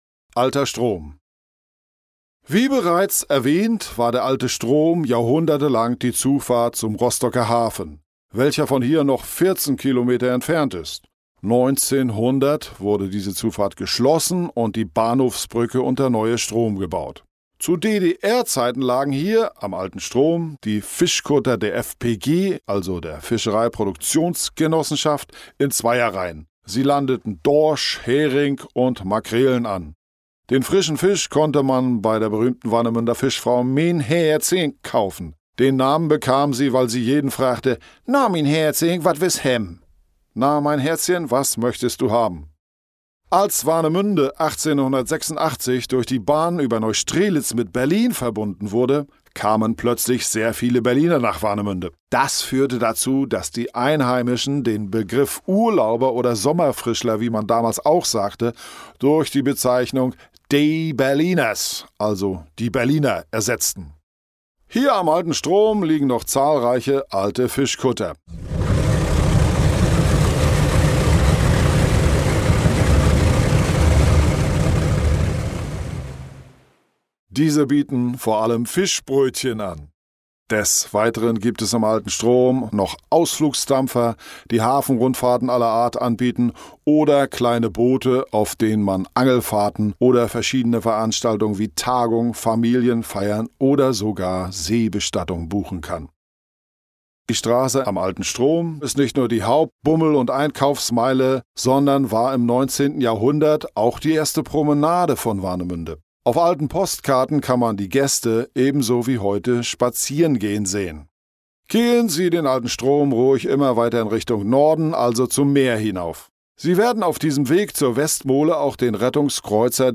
Audioguide
Aufnahmestudio: Tonstudio Rostock